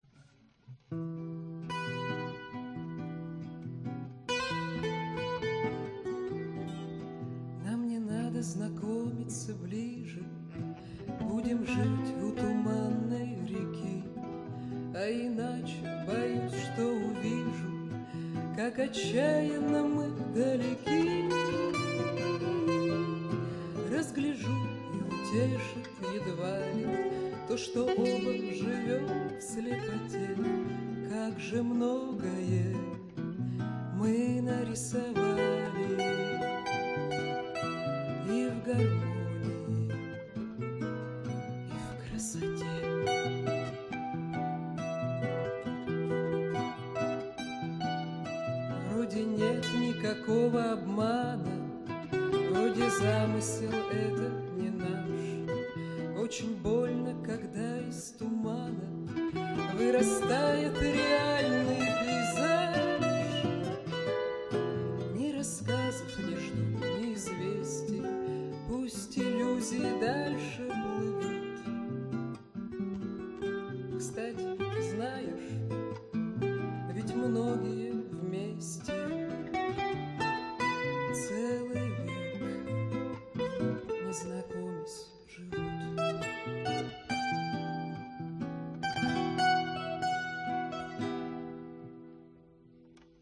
Концерт в кировской детской филармонии 10 декабря 2005 г.
вокал, гитара
Фрагменты концерта (mp3)